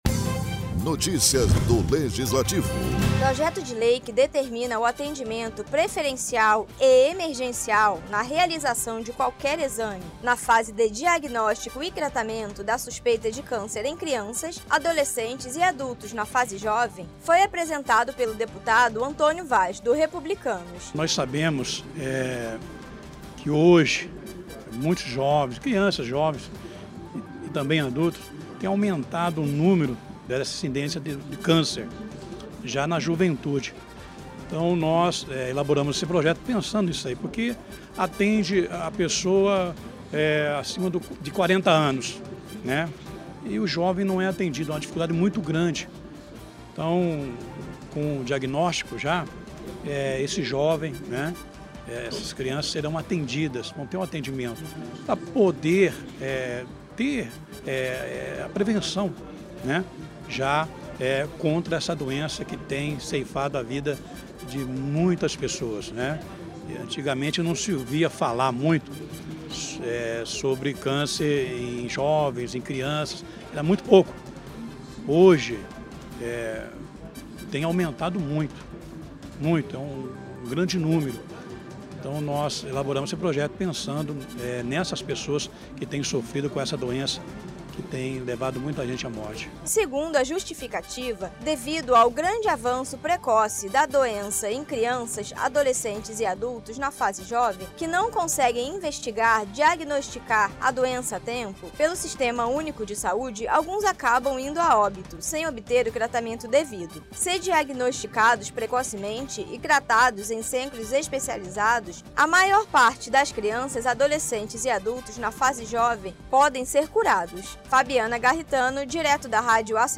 Locução e Produção